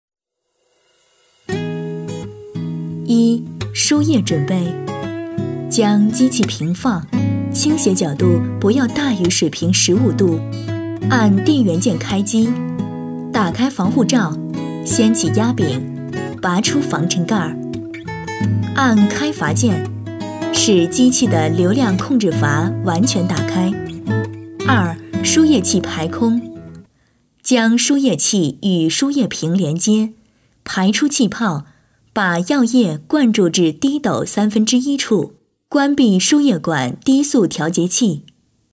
女声配音
课件女国99